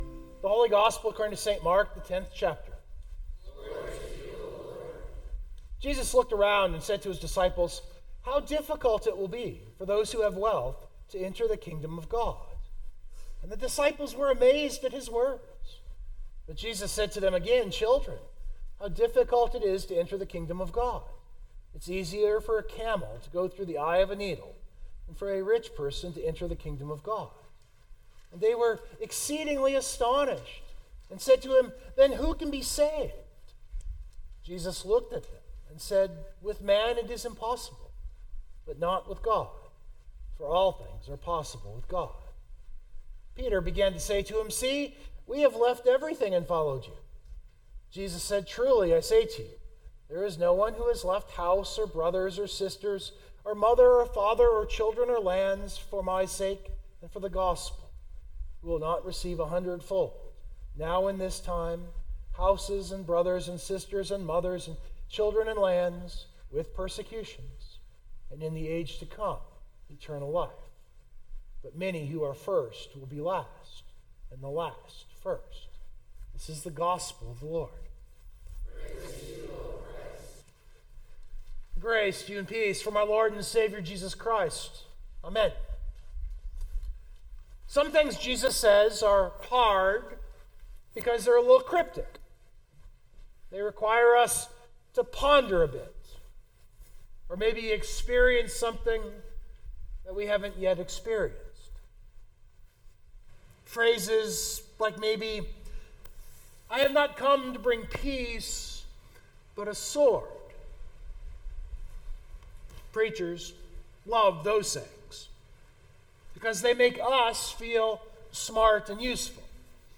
102024 Sermon Download Biblical Text